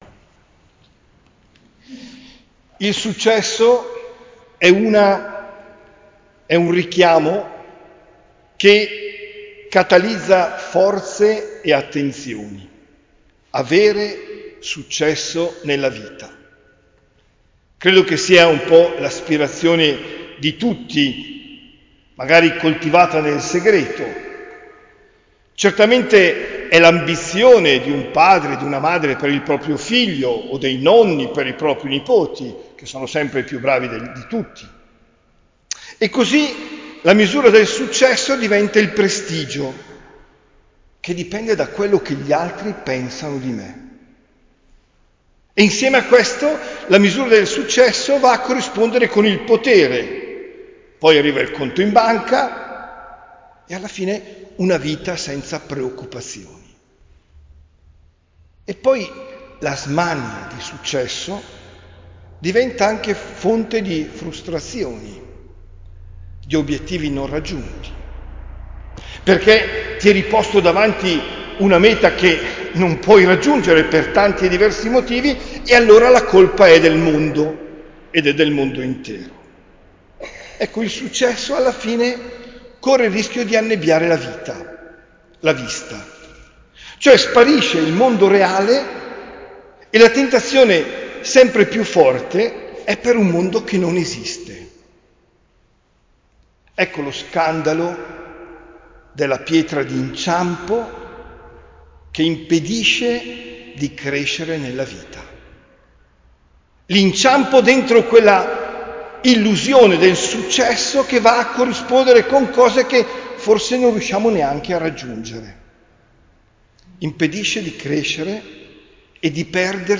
OMELIA DEL 3 SETTEMBRE 2023